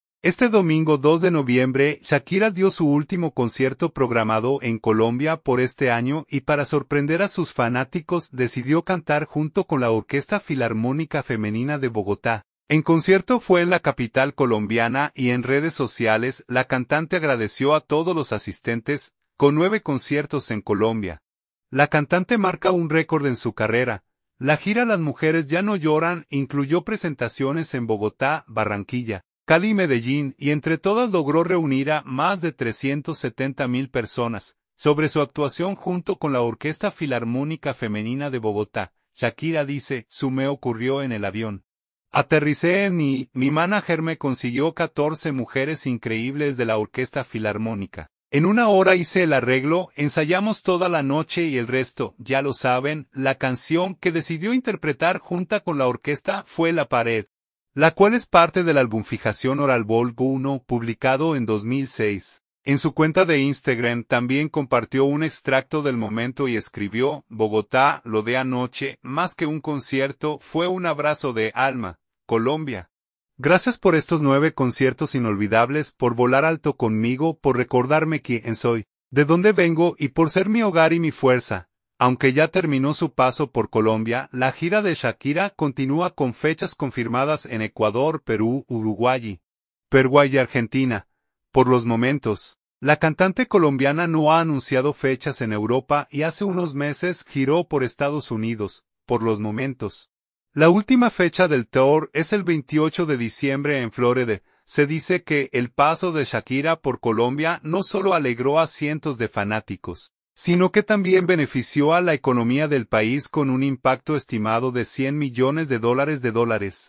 La sorpresa la dio en su reciente concierto en Bogotá.